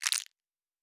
Plastic Foley 13.wav